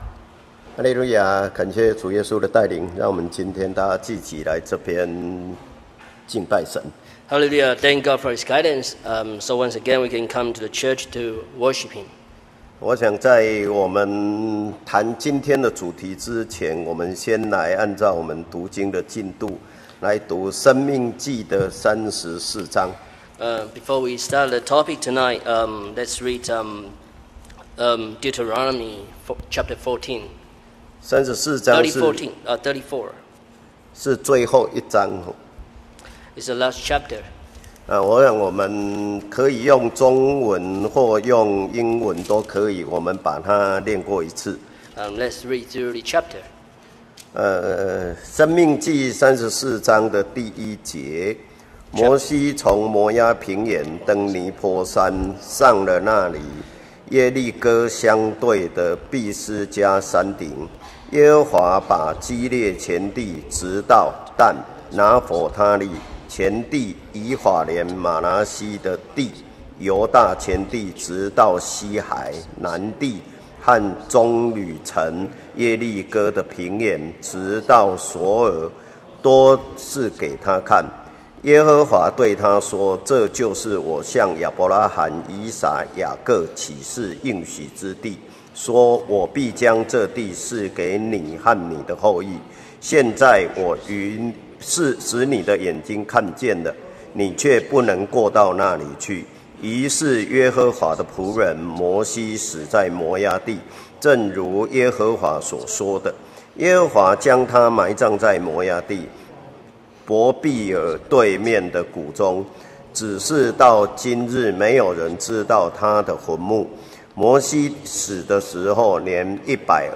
中英雙語講道聚會：與神和好-獻祭-講道錄音